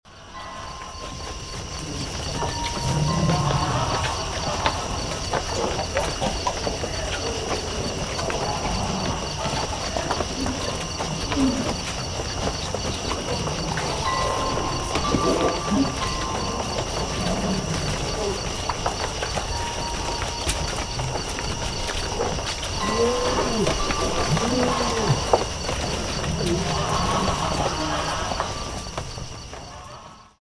Support the Big Cats with this Ambient CD!